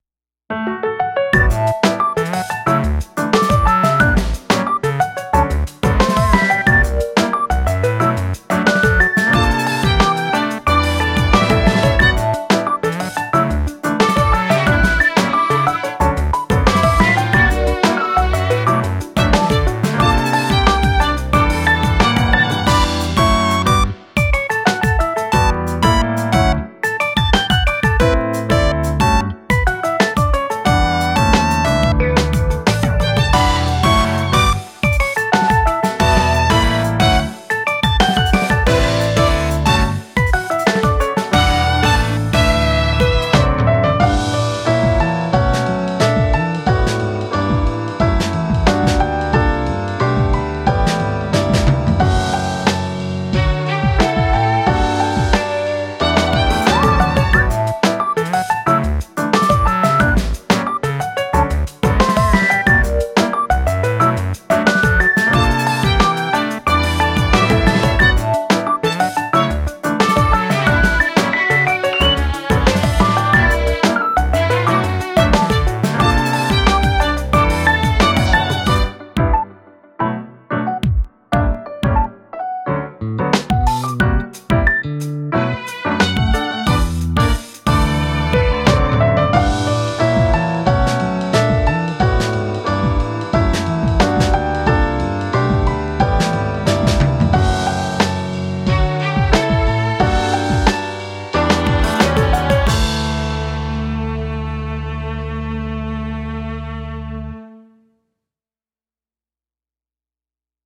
/ インスト